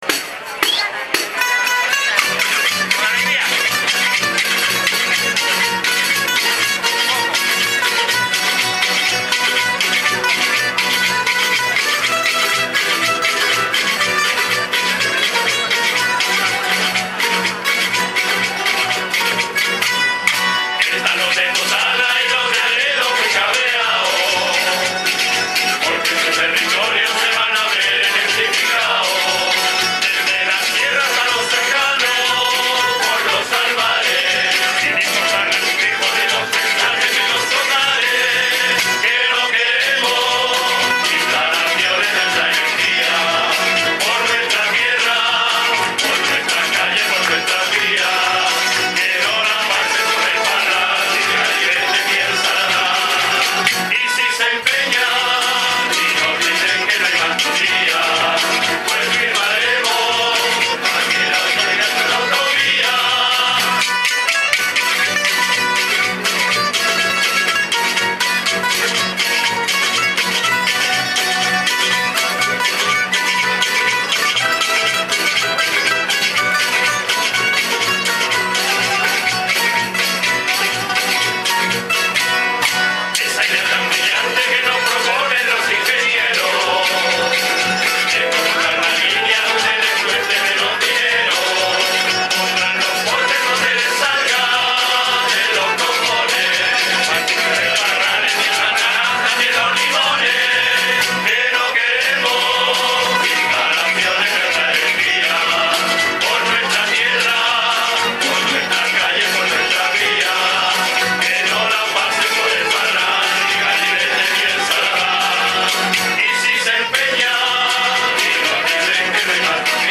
Más de 2.500 personas se congregan en la plaza de la Constitución para decir no a la construcción de la línea de alta tensión en los municipios de Totana y Aledo